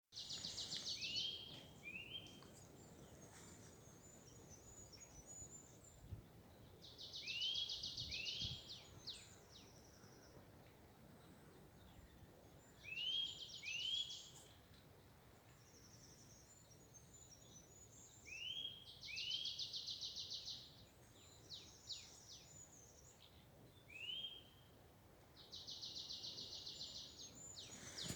Marsh Tit, Poecile palustris
Administratīvā teritorijaSaldus novads
StatusSinging male in breeding season